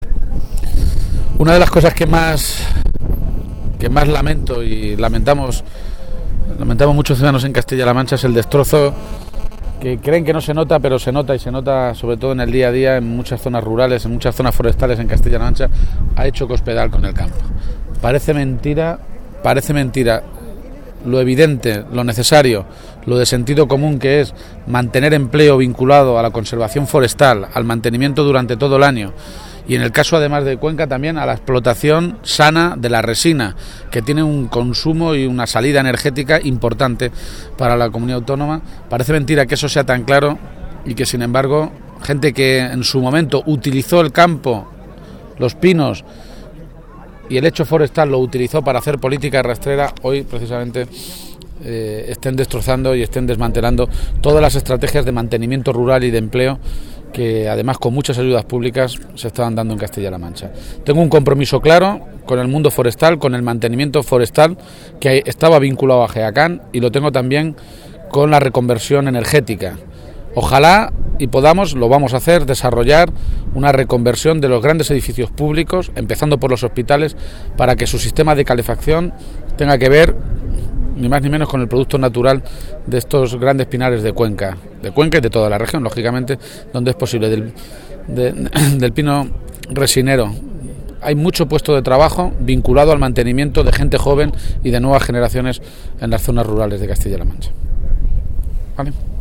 El secretario regional del PSOE, Emiliano García-Page, ha señalado hoy que si es elegido presidente de Castilla-La Mancha pondrá en marcha medidas encaminadas a apoyar las zonas forestales y rurales de nuestra región. García-Page ha realizado estas declaraciones en la localidad conquense de Almodóvar del Pinar tras visitar una explotación resinera.